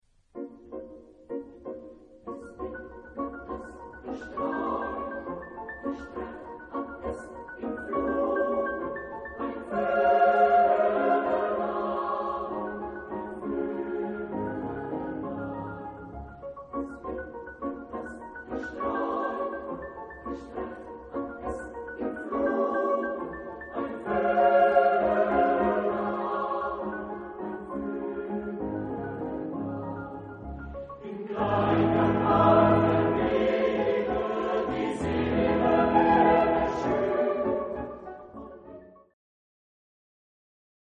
SATB (4 voces Coro mixto) ; Partitura de coro.
Romántico. Vals viénes. Canto coral. ciclo.